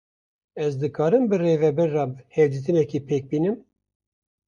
Wekî (IPA) tê bilêvkirin /reːvɛˈbɪɾ/